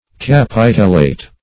Search Result for " capitellate" : The Collaborative International Dictionary of English v.0.48: Capitellate \Cap`i*tel"late\ (k[a^]p`[i^]*t[e^]l"l[asl]t), a. [L. capitellum, dim. of caput head.]